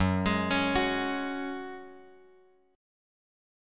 F#sus2 chord {2 x x 1 2 2} chord